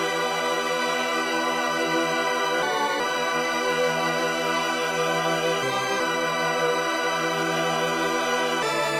OPS Starz Pad Intro Synth
描述：合成器垫组合
Tag: 160 bpm Dubstep Loops Pad Loops 1.01 MB wav Key : Unknown